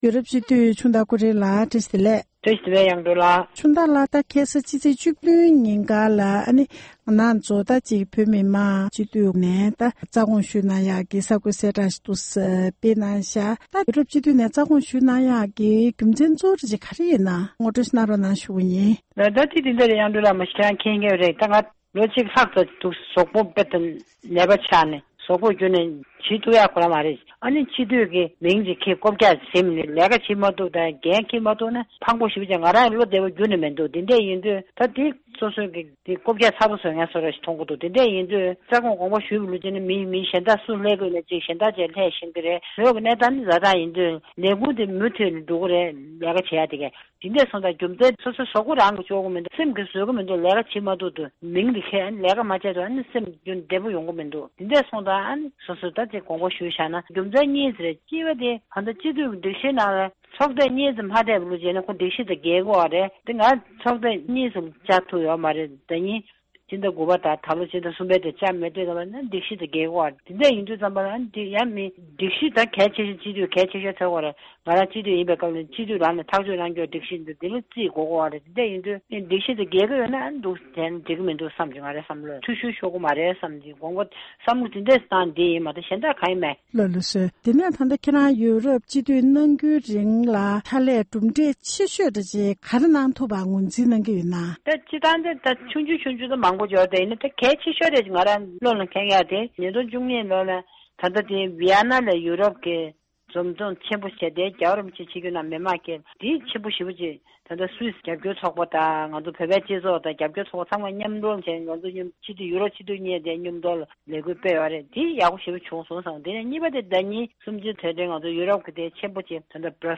ཡོ་རོབ་སྤྱི་འཐུས་ཆུང་བདག་ལགས་དགོངས་ཞུ། བསྡུས་པའི་གནས་འདྲིའི་ལེ་ཚན།